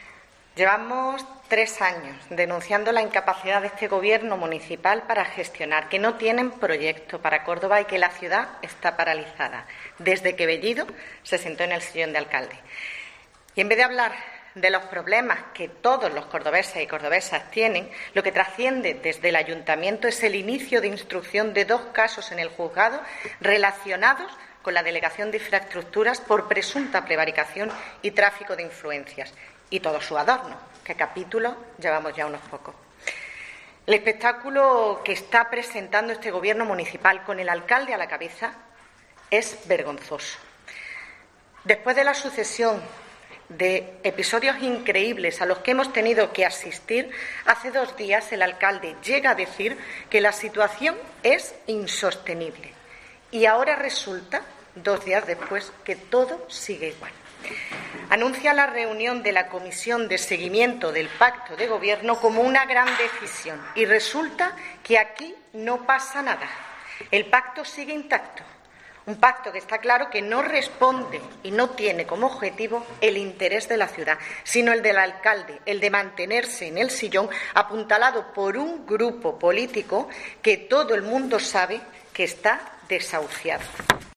Escucha a la concejal socialista, Alicia Moya, sobre el pacto de gobierno local